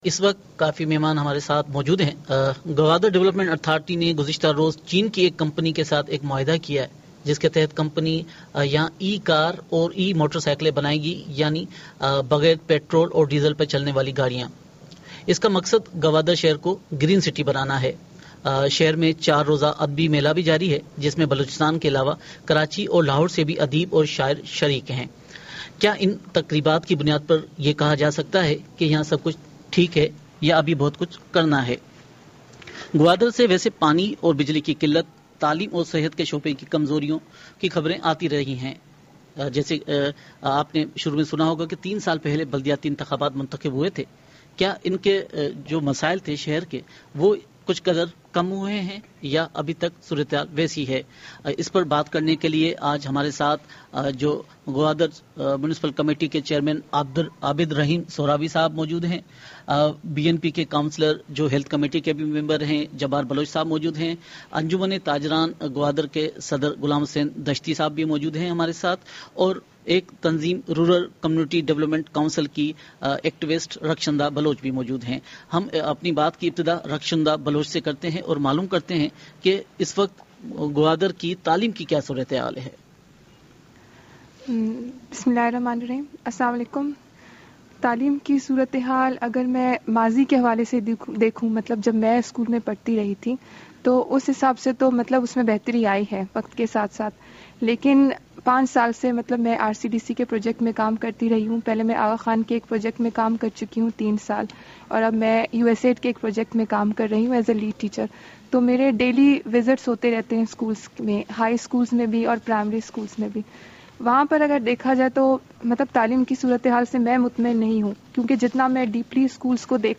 گوادر کے حوالے سے خصوصی مباحثہ